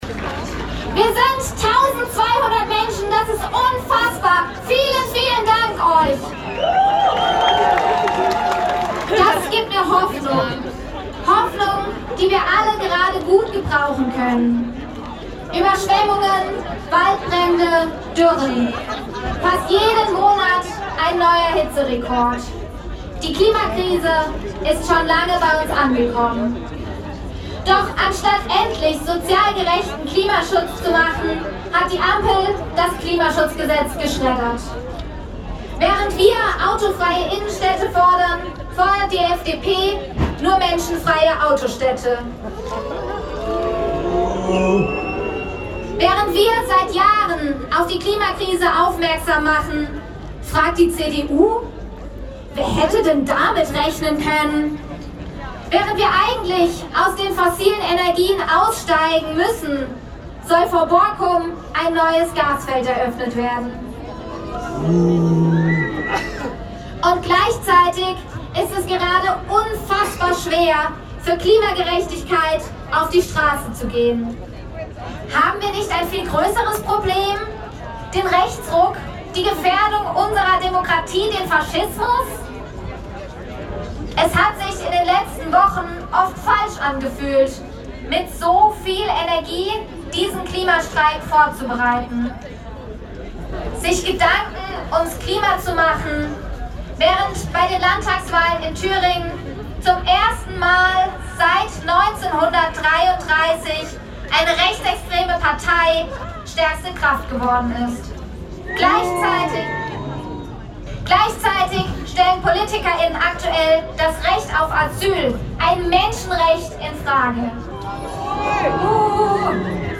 Hier hört Ihr Interviews mit Redner*innen, mit Streikenden und die Abschlusskundgebung von Fridays for Future.
Abschlusskundgebung des Klimastreiks